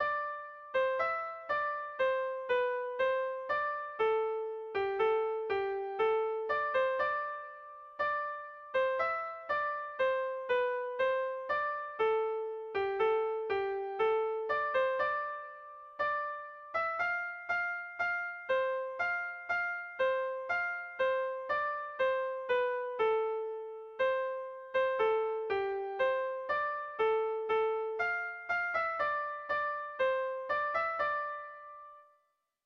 Gabonetakoa
Zortziko ertaina (hg) / Lau puntuko ertaina (ip)
AABD